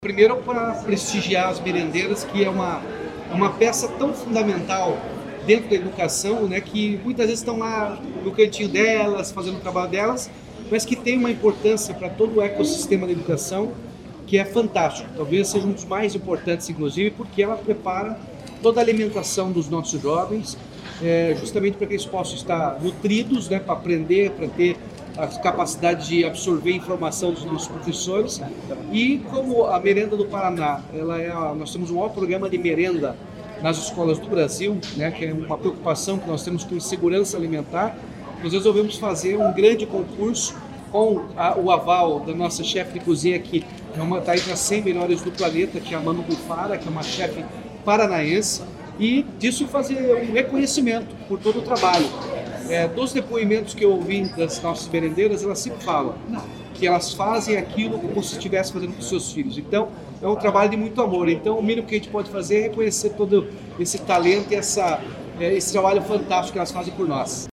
Sonora do governador Ratinho Junior sobre o anúncio das vencedoras do concurso Melhor Merenda do Paraná